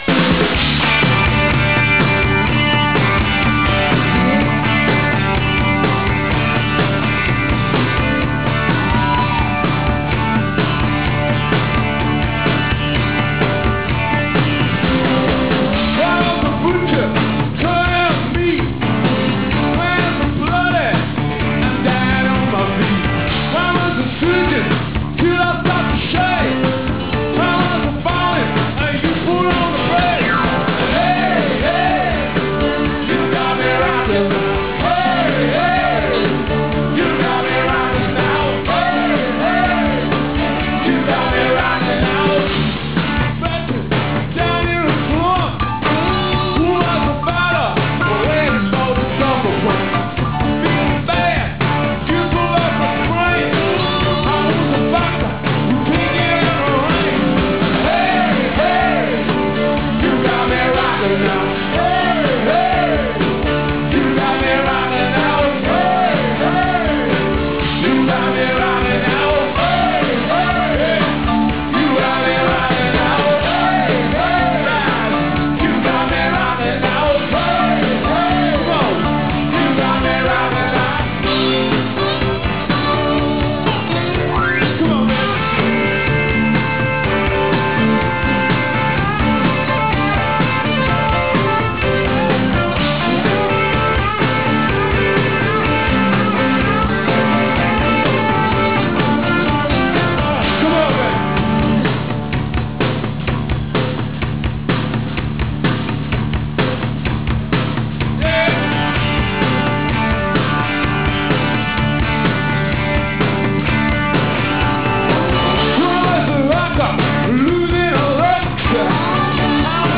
Live Audio Clips
Foxboro Sep. 5th